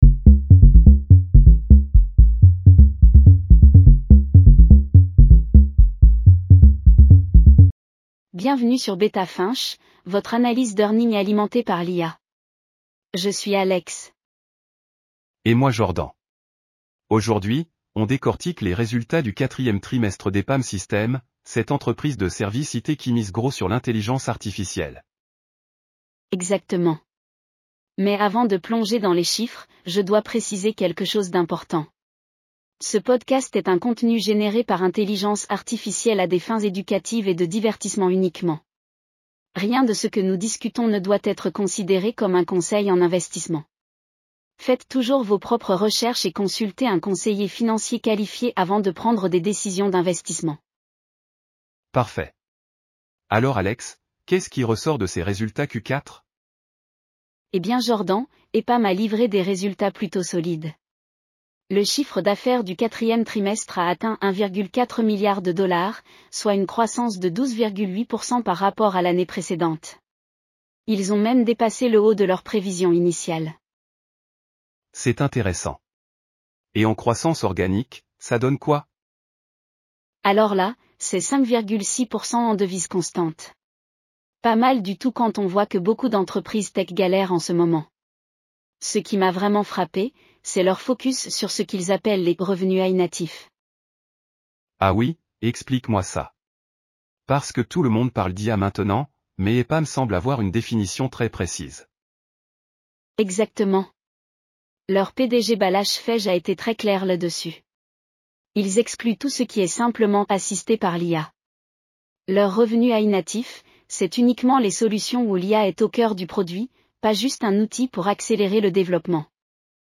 • AI-generated insights and analysis